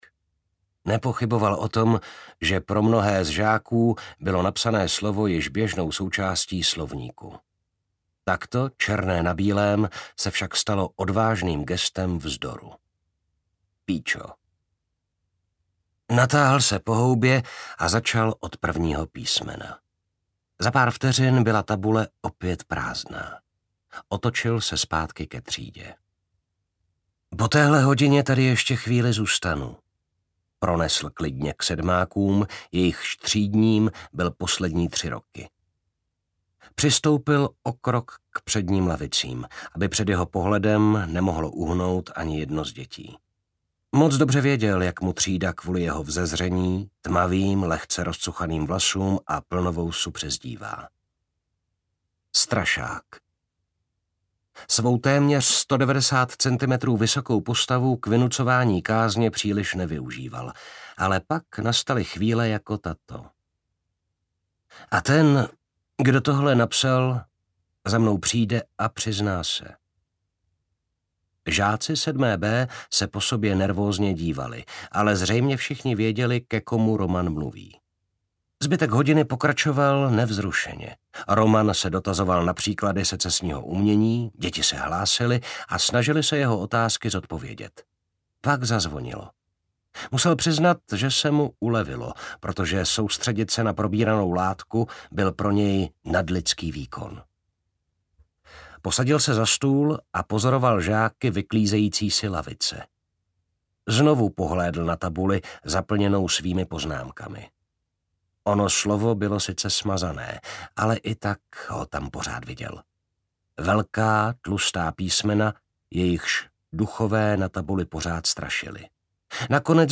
Černý kos audiokniha
Ukázka z knihy
cerny-kos-audiokniha